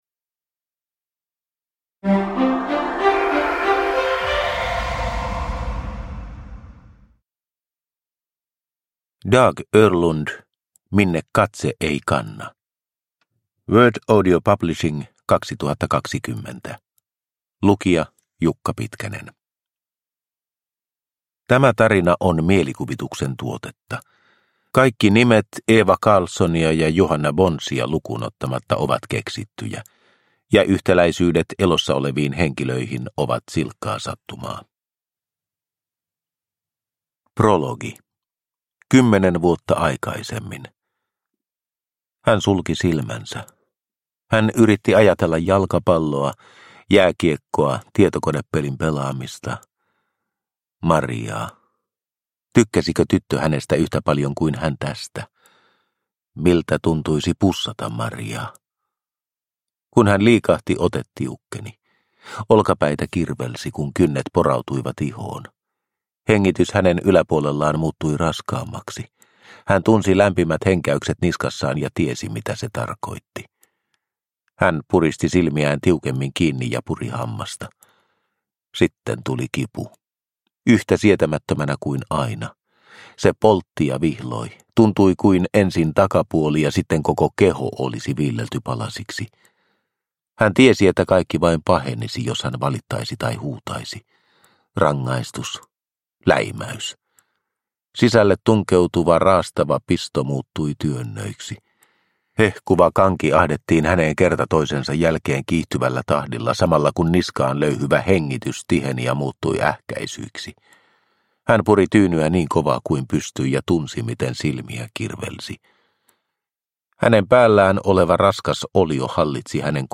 Minne katse ei kanna (ljudbok) av Dag Öhrlund